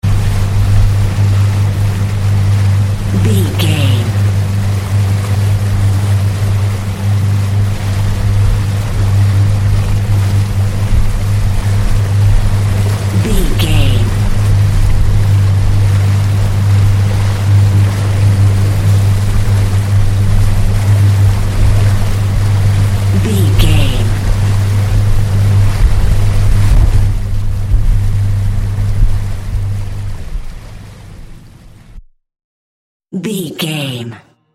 Onboard a motor boat at medium throttle . Hi def stereo audio.
Motor Boat onboard sound
Sound Effects
motor-boat-baord48750.mp3